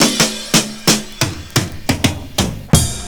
FILL 6    -R.wav